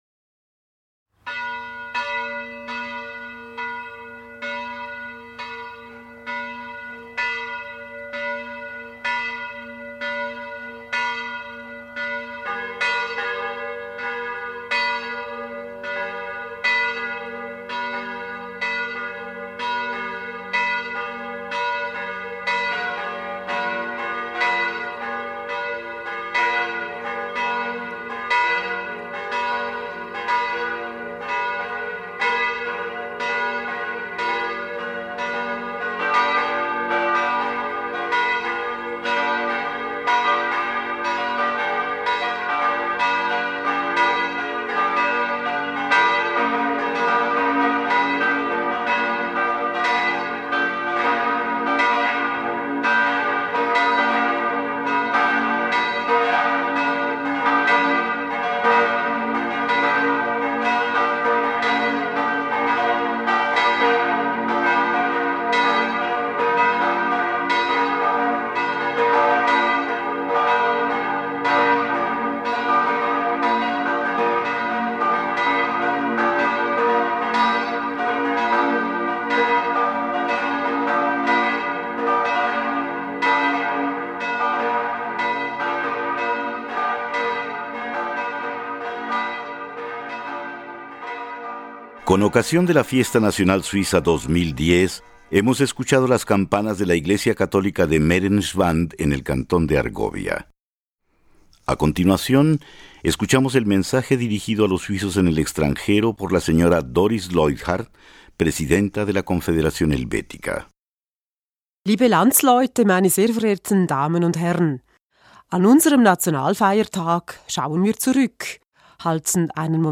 Discurso de la presidenta de la Confederación Doris Leuthard a los suizos del exterior.